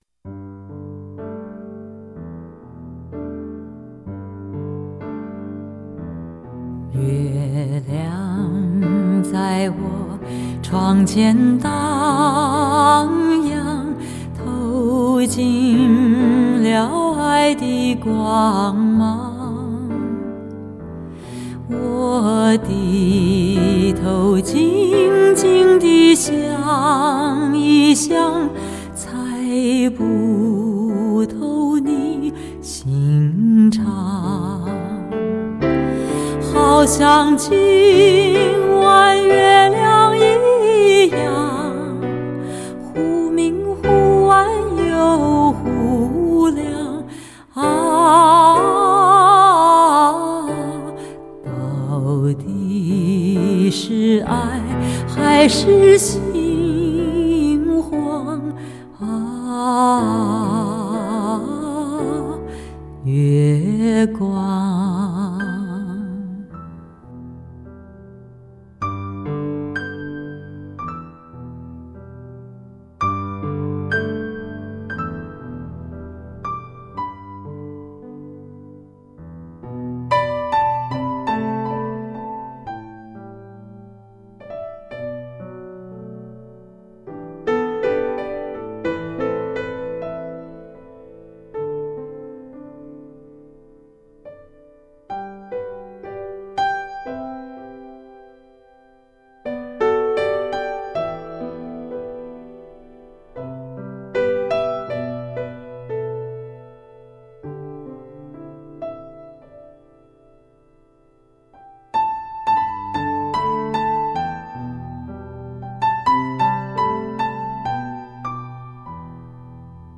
她波澜不惊的歌声，是一种被遗忘了的古老语言，有着一种古典的浪漫，一种优雅的感伤。
嗓音低回委婉，淳厚沉稳，极富感染力，是那种让你平静的歌手，把声音平铺在你的面前，不着任何渲染的笔墨却已然色彩斑谰。
她的中音是歌手中少见的。